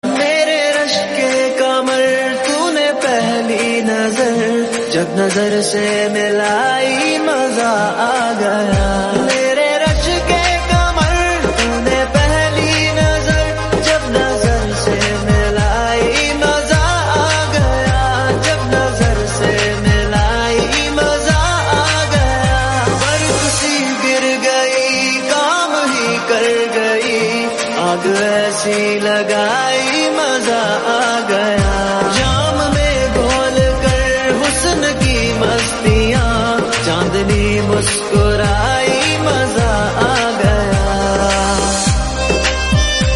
Ringtones Category: Top Ringtones